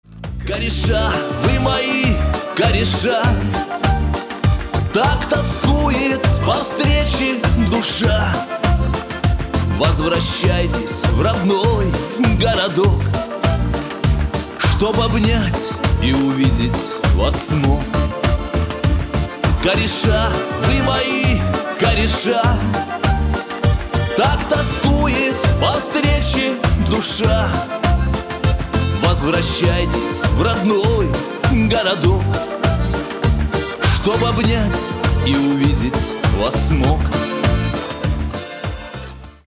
мужской вокал
грустные